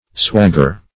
Swagger \Swag"ger\, n.